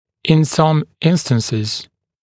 [ɪn sʌm ‘ɪnstənsɪz][ин сам ‘инстэнсиз]в некоторых случаях